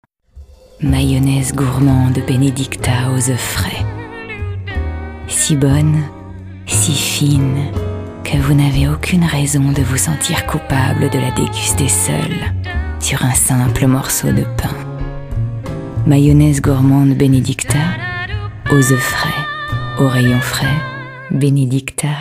Actrice,voix off et présentatrice.
Sprechprobe: Werbung (Muttersprache):
ACTRESS AND FRENCH VOICE